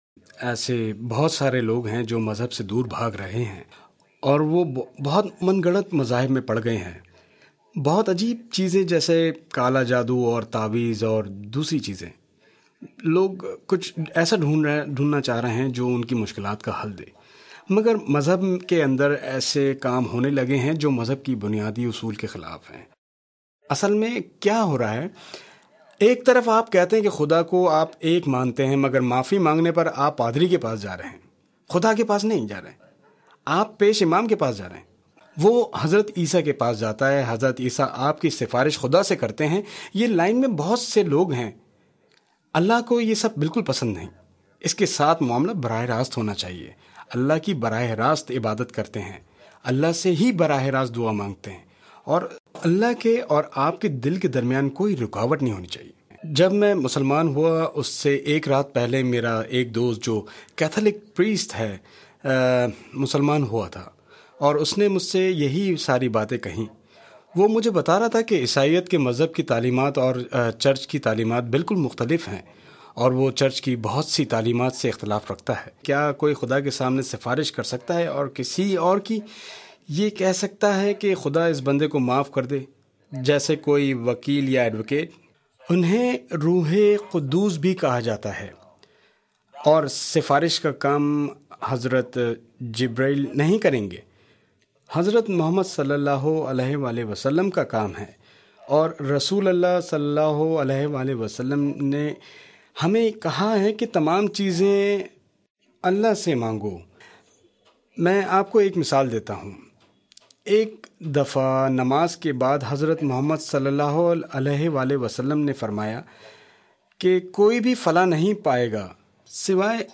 Translated into Urdu.